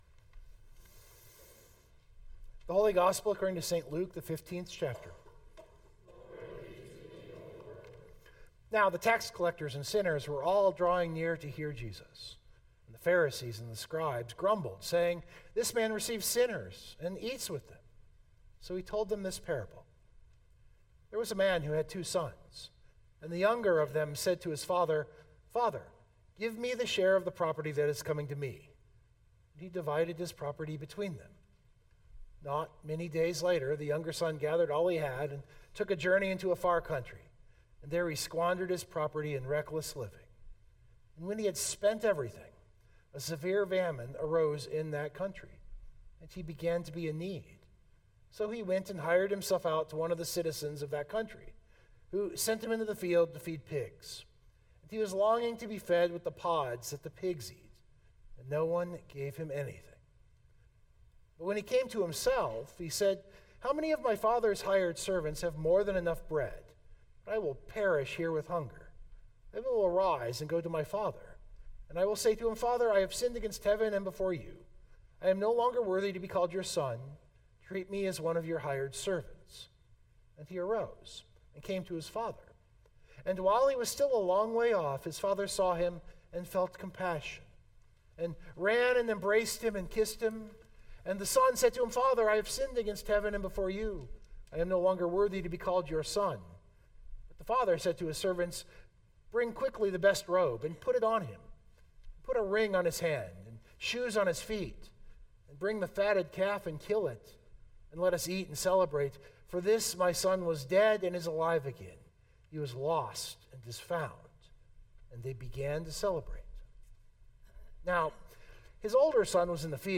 This sermon is an attempt to do that.